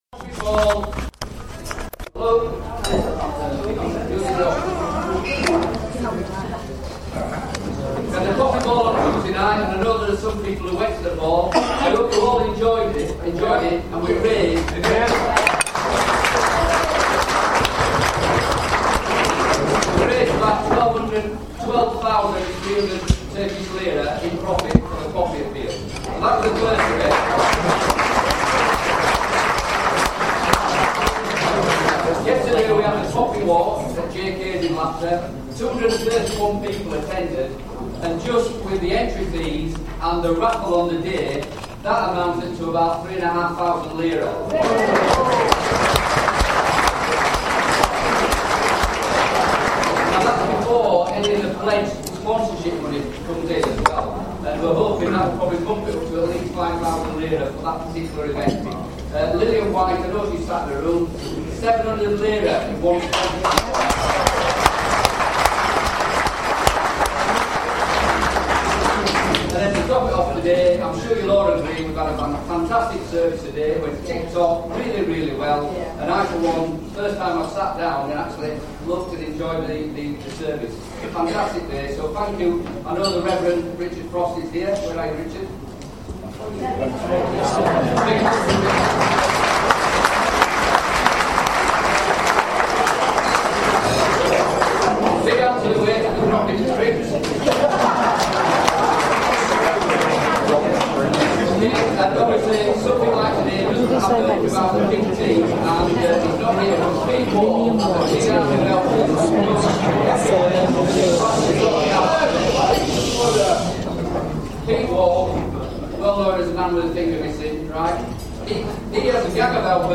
Following the beautiful Remembrance Sunday service at the Old British Cemetery, a large group of RBL members made their way to the Ship Inn, Karaoğlanoğlu for lunch.
poppy-day-speeches-62.mp3